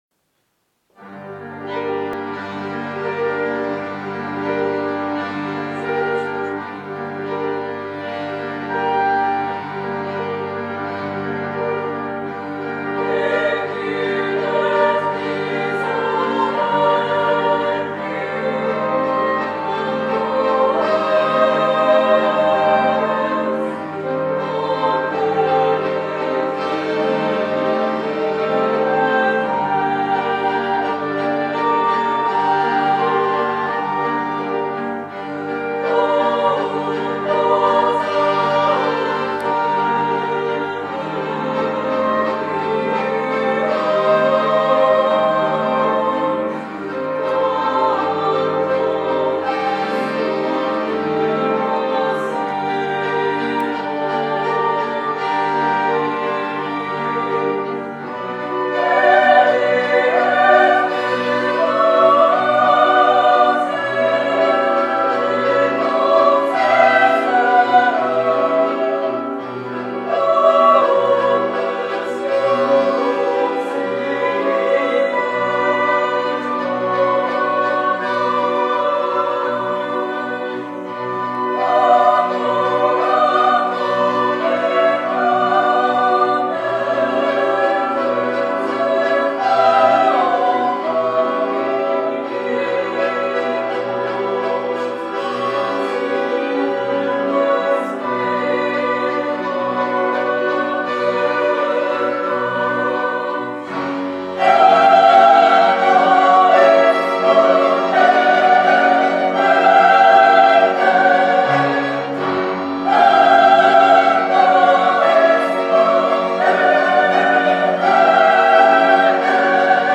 polska muzyka współczesna
chór żeński